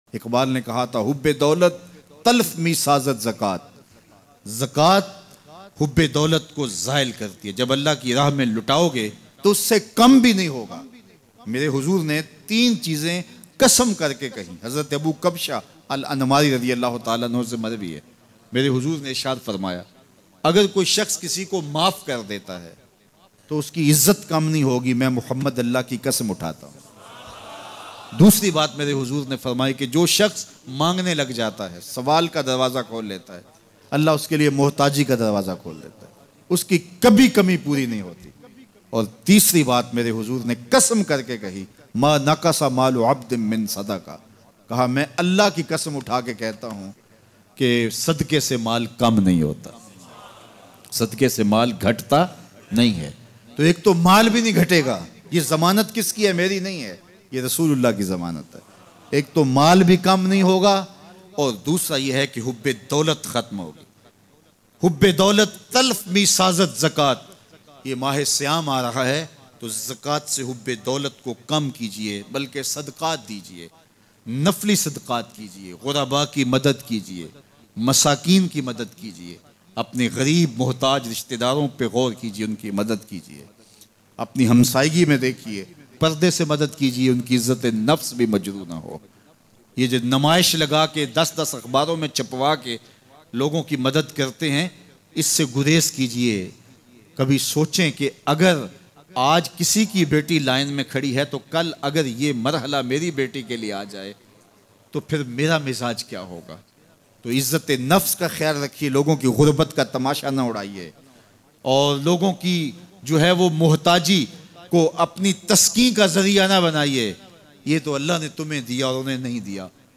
HUZOOR ne 3 Cheezo ki Guaranty de di Bayan MP3